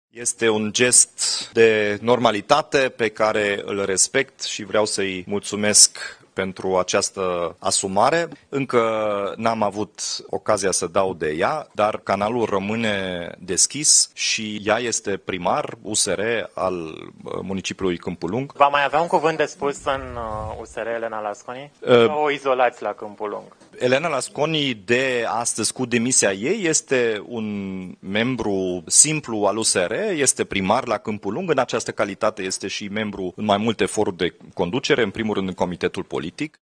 USR votează luni, 5 mai, dacă îl va susține pe Nicușor Dan în turul II în alegerile prezidențiale, a anunțat președintele interimar al partidului, Dominic Fritz – la o conferință de presă.